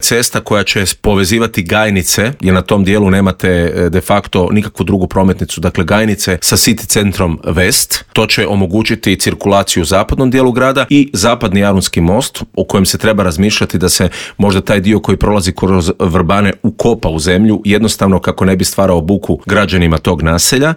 U studiju Media servisa ugostili smo nezavisnog kandidata za gradonačelnika Zagreba Davora Bernardića kojeg uvjerljivi trijumf Milanovića nije iznenadila jer su trendovi bili neupitni: